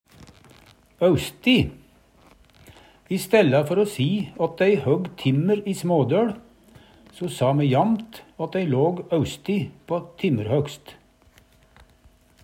austi - Numedalsmål (en-US)
Høyr på uttala Ordklasse: Adverb Attende til søk